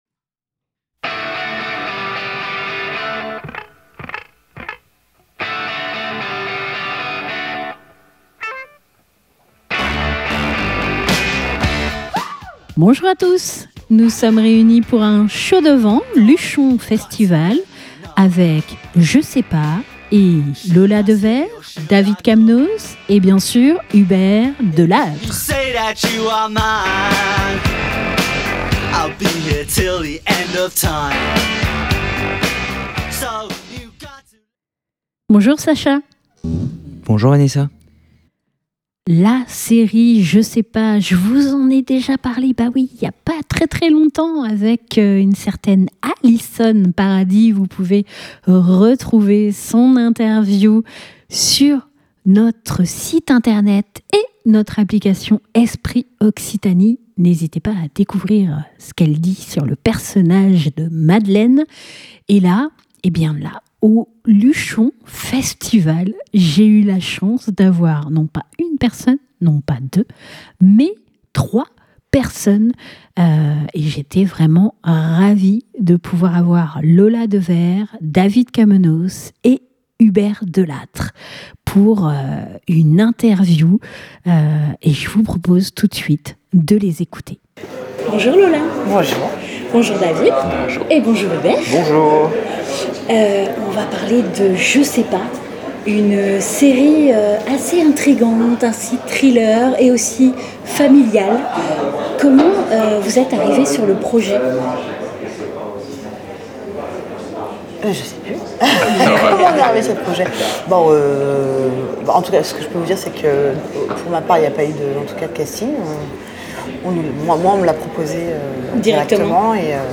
Lors du Luchon festival nous avons pu échanger avec Lola Dewaere, David Kammenos et Hubert Delattre sur leur rôle respectif dans la série